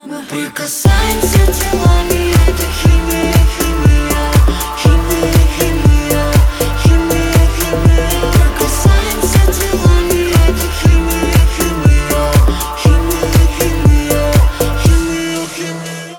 ритмичные
клубнячок